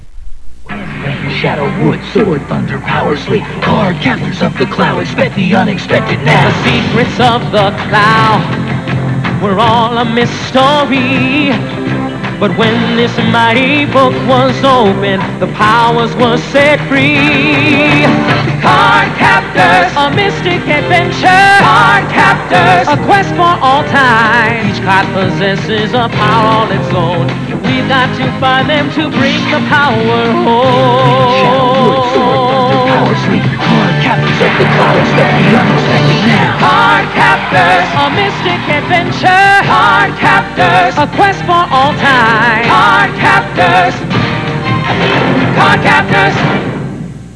The quality is pretty good, so listen to 'em!